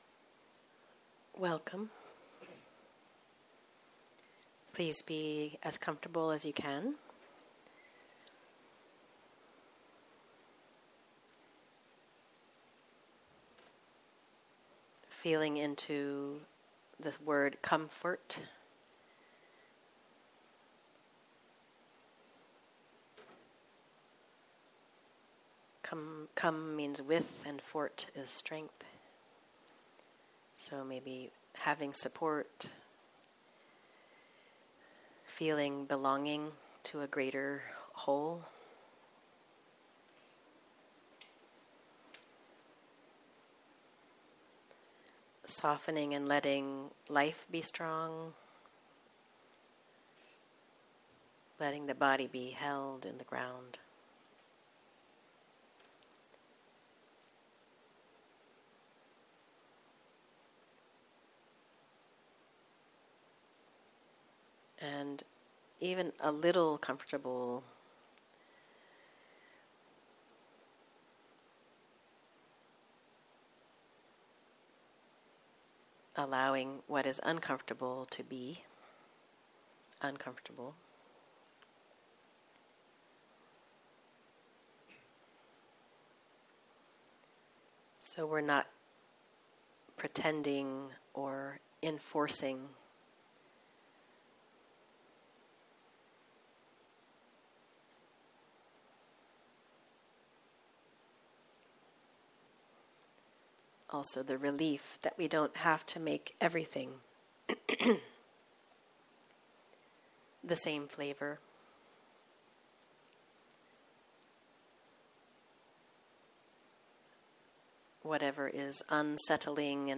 בוקר - מדיטציה מונחית